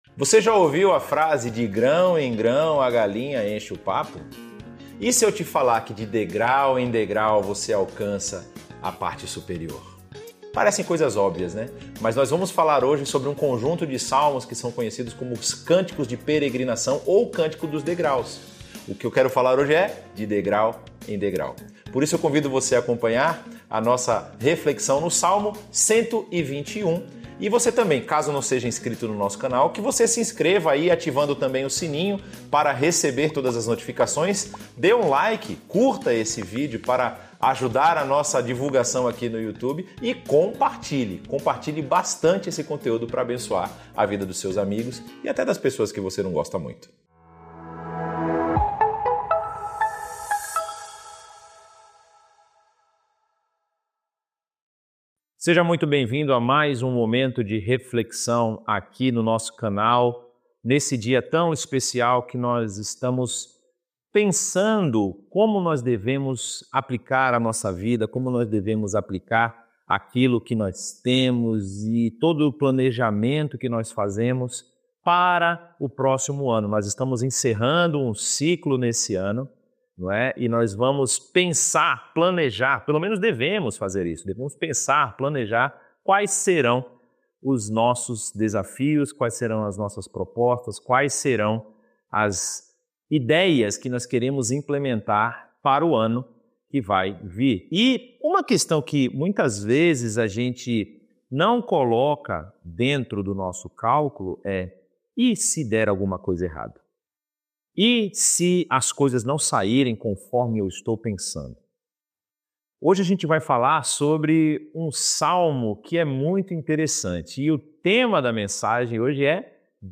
Igreja Batista Nações Unidas